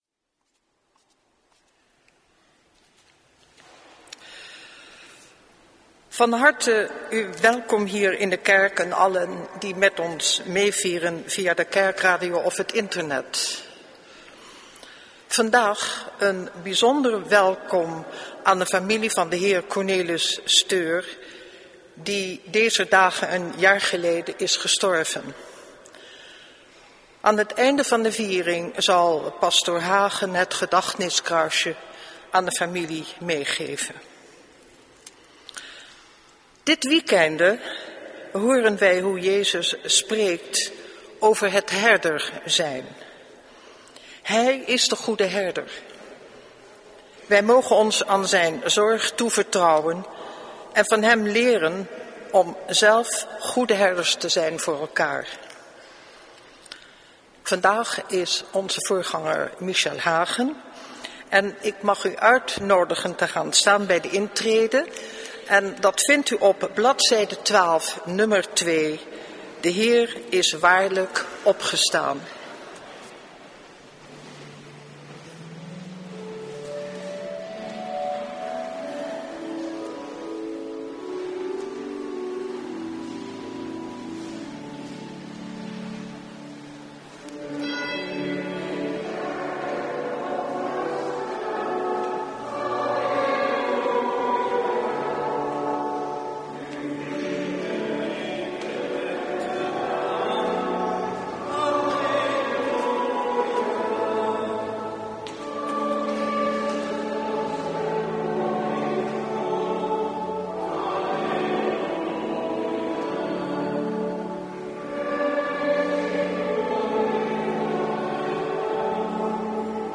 Lezingen
Eucharistieviering beluisteren (MP3)